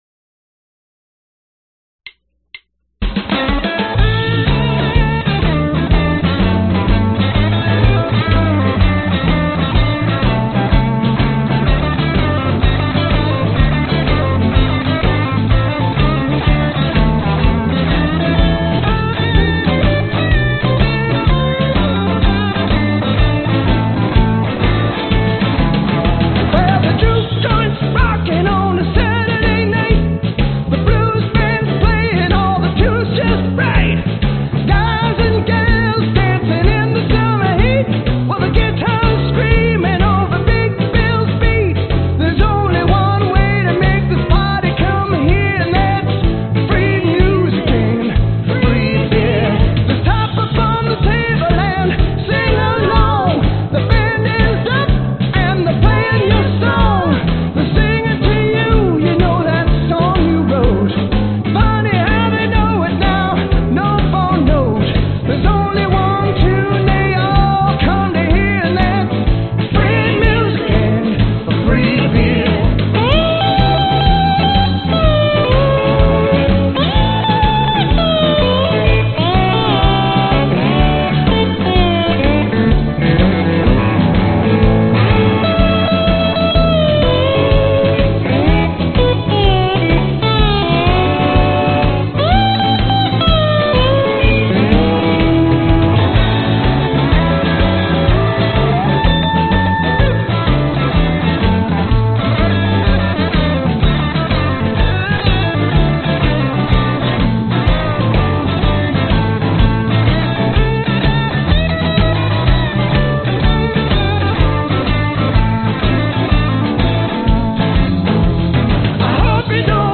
描述：用一把调低了的木吉他来演奏这段旋律。我已经单独上传了它，它的调子是CMajor。
一些节拍和低音
Tag: 女声 男声 流行 吉他 贝斯 快乐